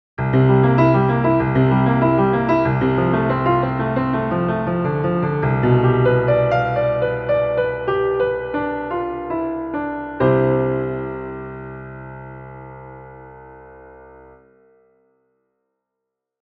The last 4 measures all resting on C. Even though the progression above the pedal is C7 – F – G7 – C, the constant C pedal in the bass creates an overall sense of rest and stability. Especially listen for the dissonant rub of the pitch B from the G7 chord, fighting to resolve to the home pitch of C.